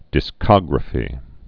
(dĭ-skŏgrə-fē)